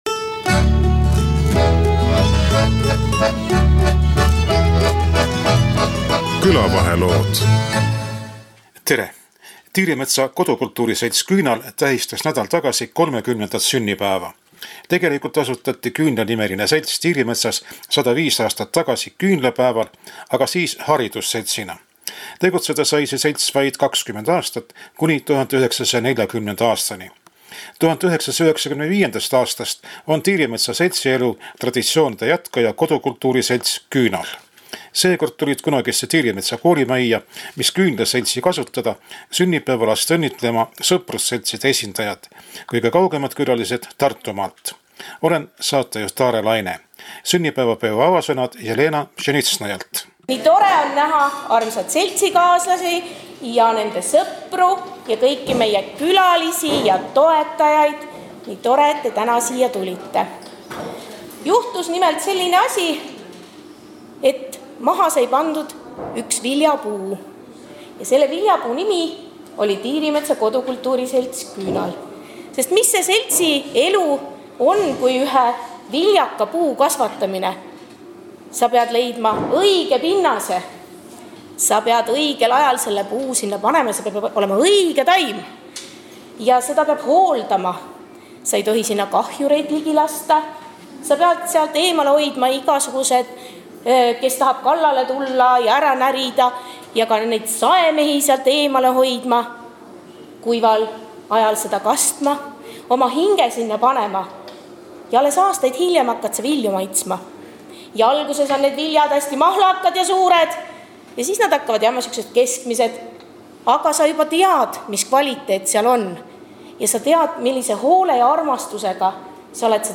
Tiirimetsa kodukultuuriseltsi Küünal hakkajad naised kutsusid raadiomehe külla, seltsi 30. sünnipäevale.
Taritu meesansambel õnnitles seltsi liikmeid ja külalisi mitme lauluga. Ka Taritu meeste lood kõlavad saates.